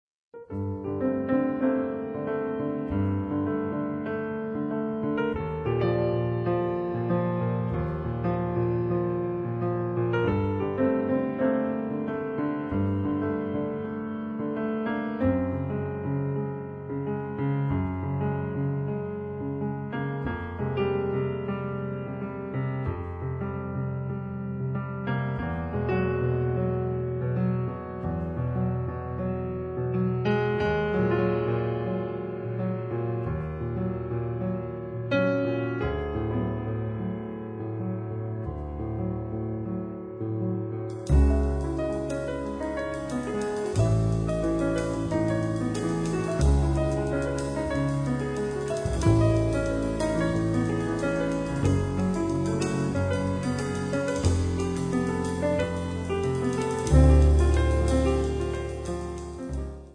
piano, hammond
contrabbasso
batteria
Ricca di vibrazioni e lirismo